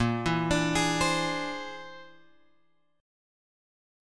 A# 9th Flat 5th